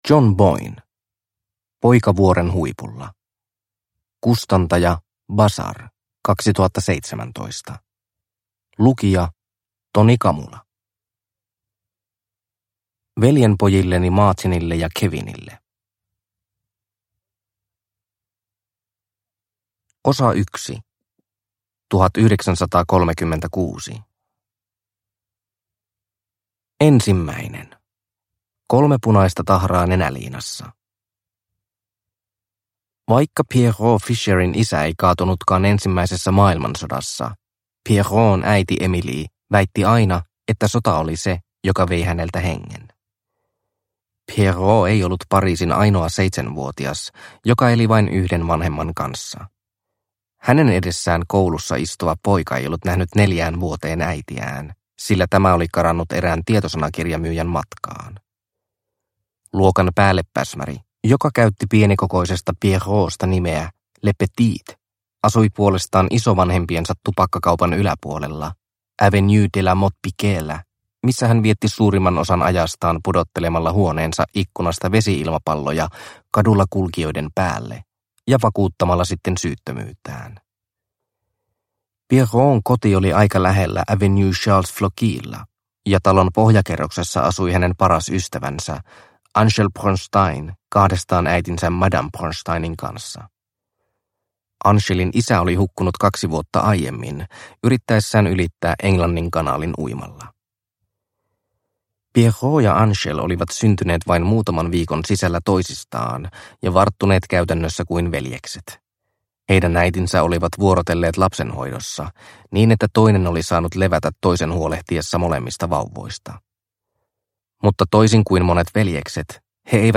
Poika vuoren huipulla (ljudbok) av John Boyne